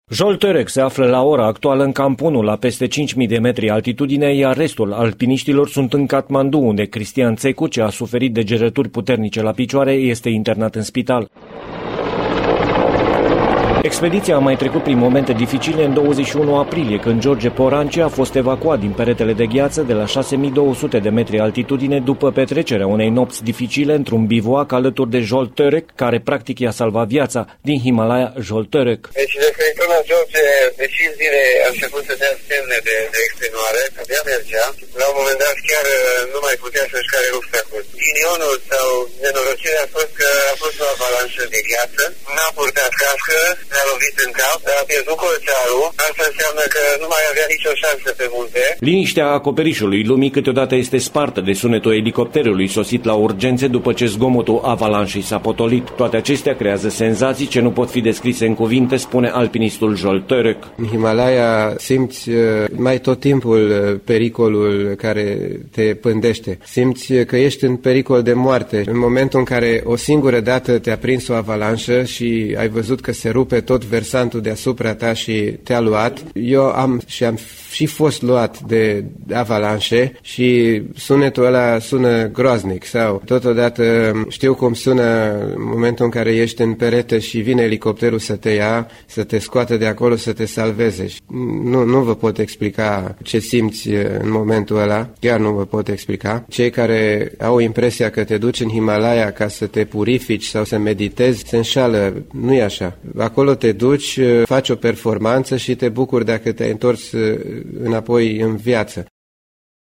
Corespondentul Radio România